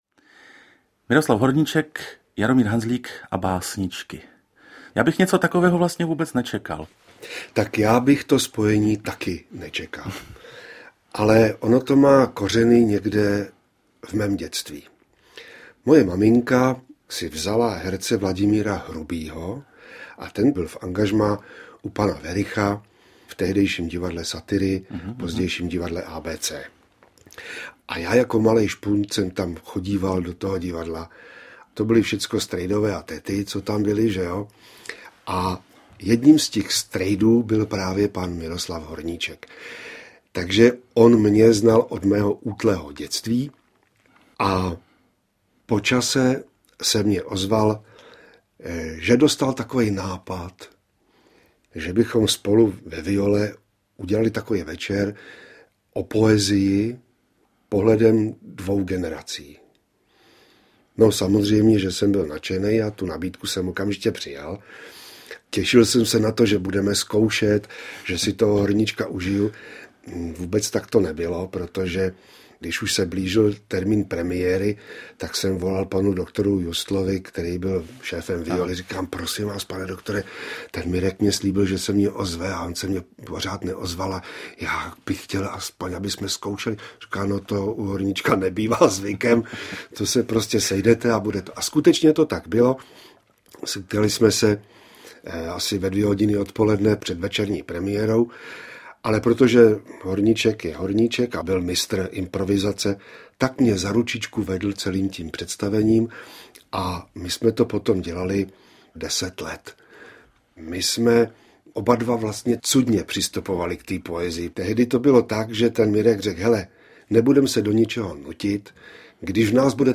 Záznam jednoho večera v poetické vinárně Viola (1988) zachycuje setkání dvou osobností našeho divadla, jejichž vyprávění vás přesvědčí, že za určitých okolností se i obyčejné může stát nevšedním.
Jaromír Hanzlík a Miroslav Horníček si povídají o životě a poezii, říkají verše, vzpomínají na zážitky...